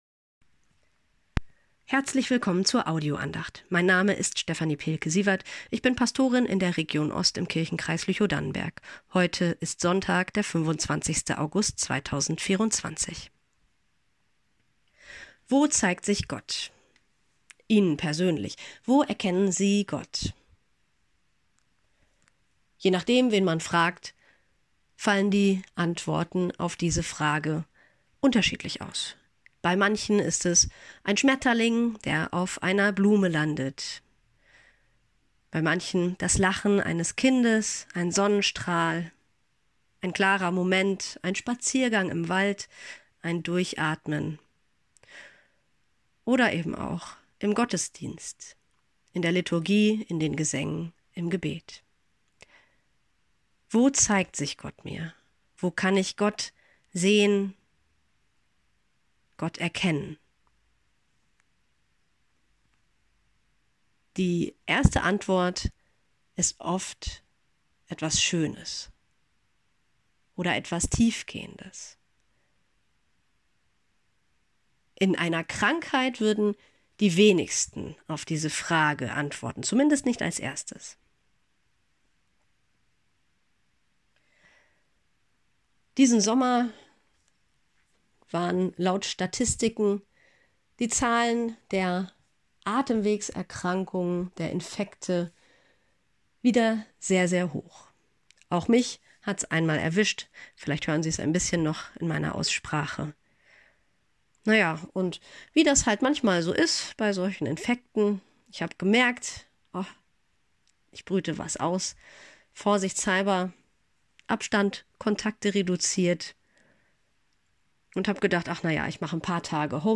Wo zeigt sich Gott ~ Telefon-Andachten des ev.-luth. Kirchenkreises Lüchow-Dannenberg Podcast